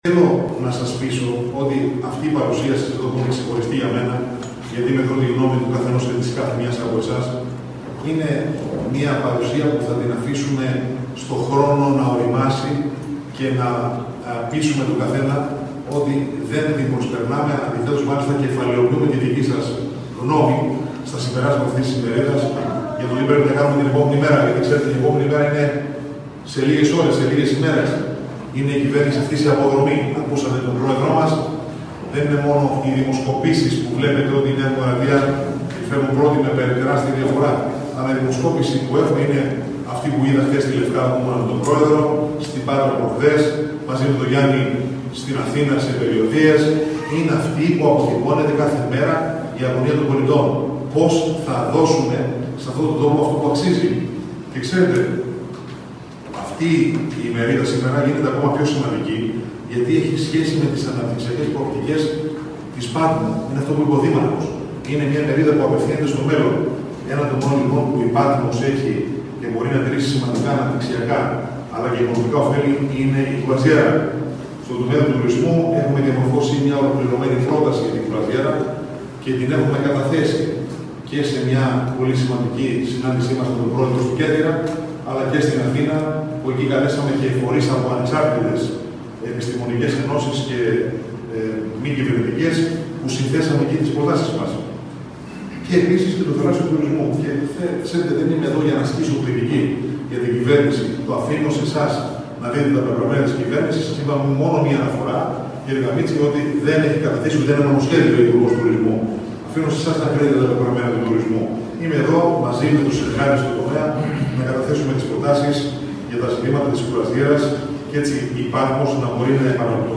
Ο Βουλευτής Δωδεκανήσου, κατά τη διάρκεια της ομιλίας του στην Ημερίδα που διοργανώθηκε από την Ένωση Νέων Αυτοδιοικητικών και το Δήμο Πάτμου, κατέθεσε τις δικές του προτάσεις για την ανάπτυξη της κρουαζιέρας και του θαλάσσιου τουρισμού στην Πάτμο.